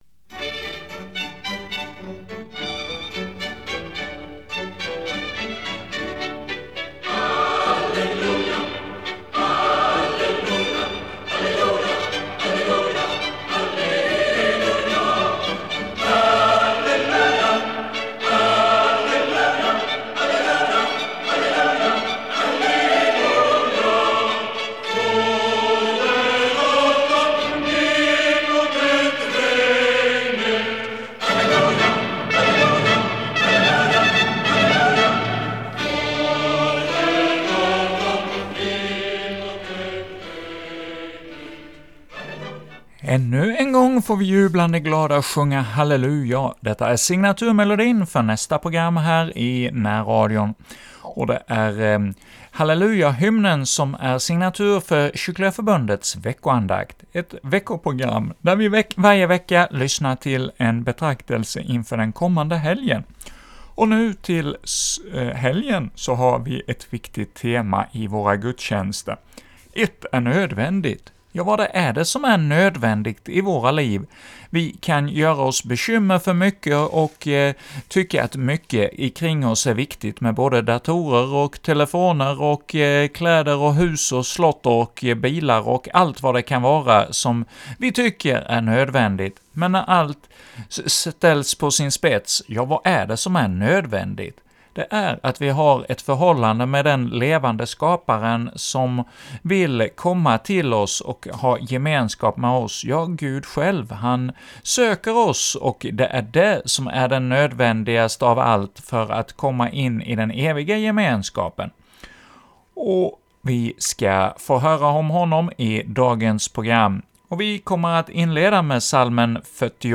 leder andakt inför 15 Söndagen efter trefaldighet